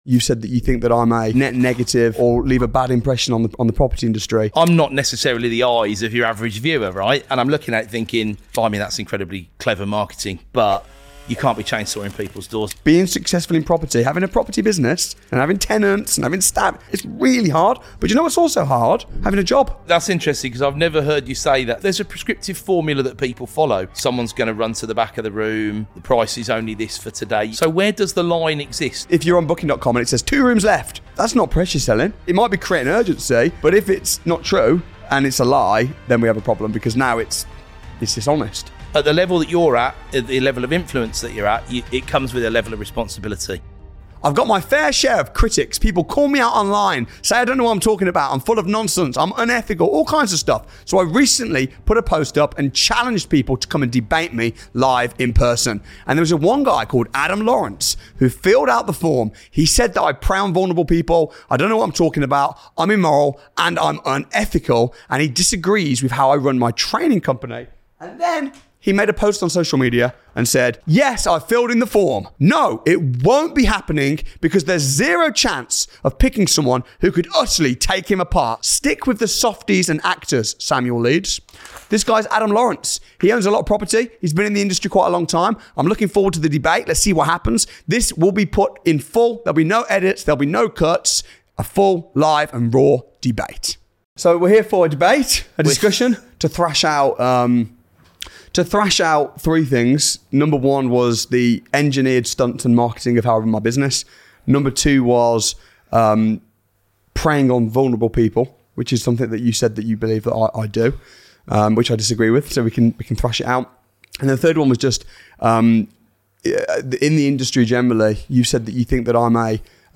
I Debated My Biggest Critic